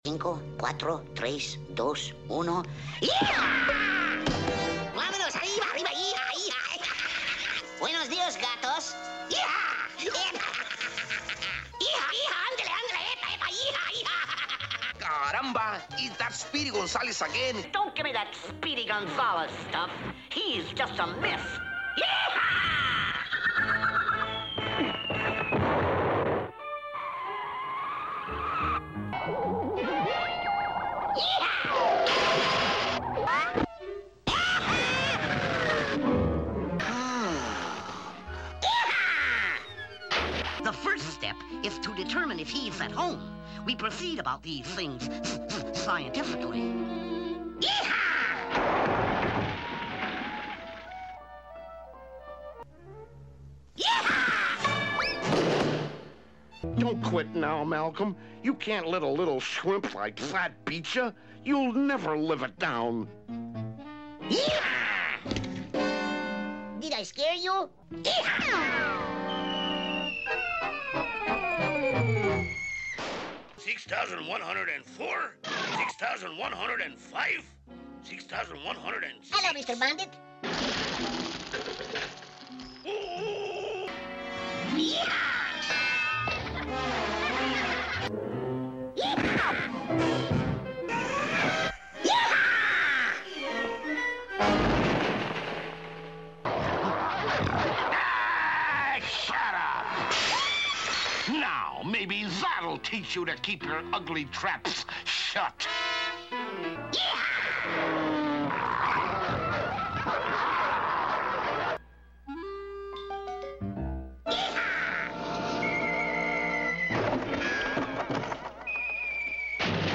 Almost 3 minutes of Speedy Gonzales scaring everyone with his YEEHAW scream!!!